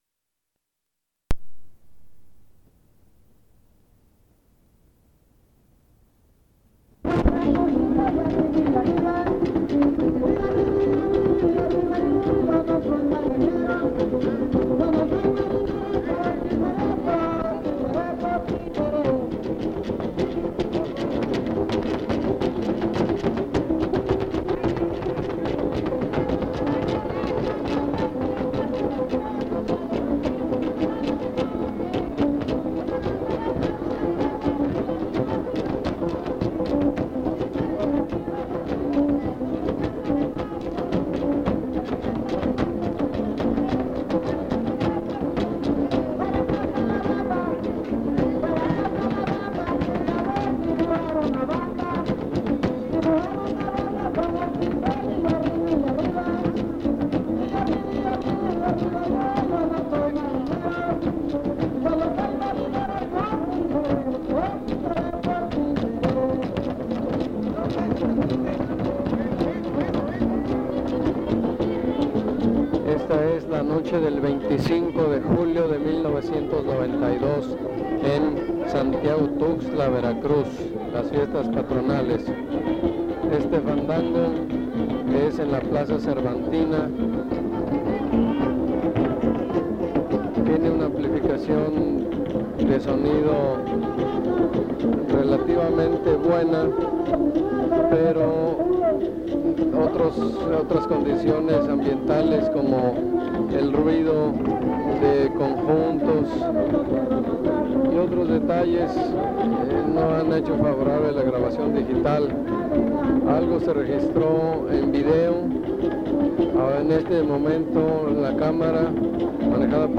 01 Fiestas patronales: fandango
Genero o forma Crónica
Location Santiago Tuxtla, Veracruz, Mexico
Is part of Fiesta del Señor Santiago: fandango